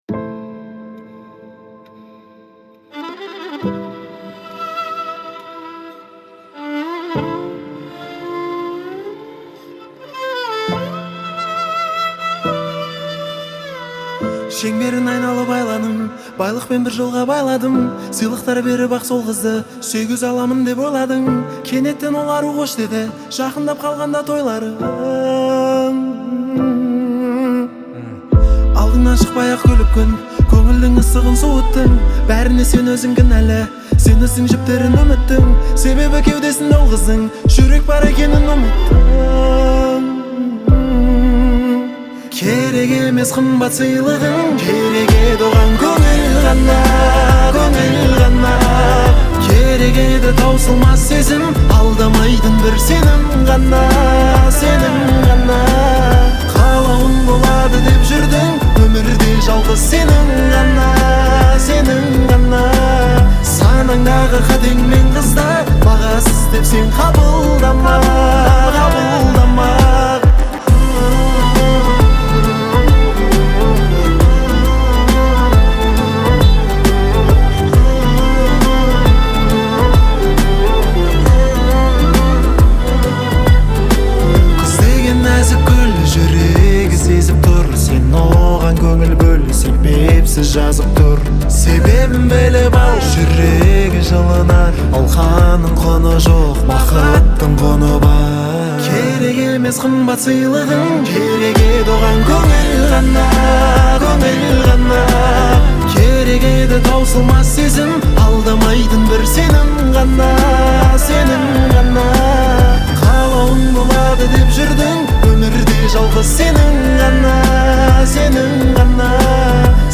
Качество: 320 kbps, stereo
Казахская музыка